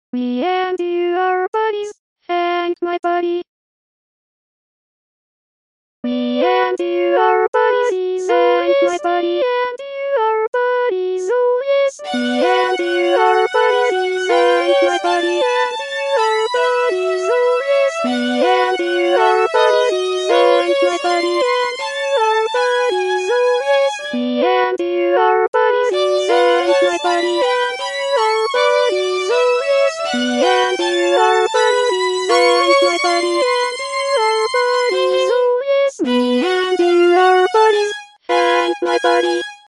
Me and You are Buddies, Hank, my Buddy MP3 format For two mezzo sopranos and violin.